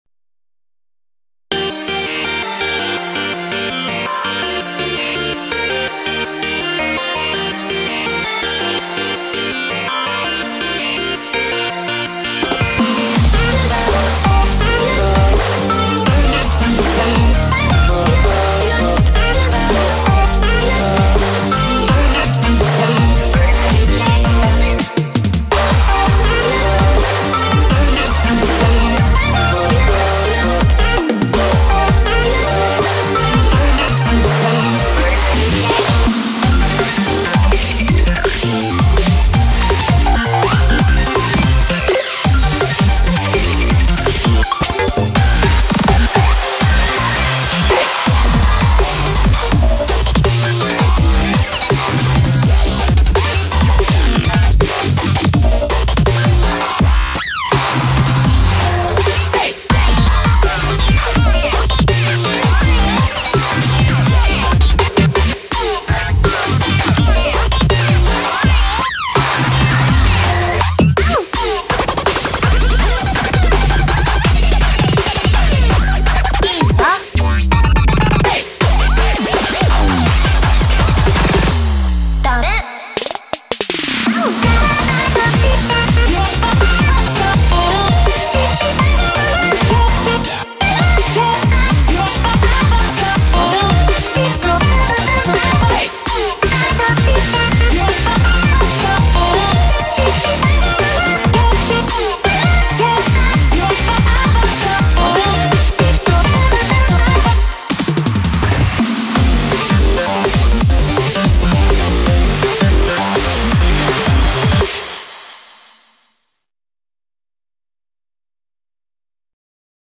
BPM83-165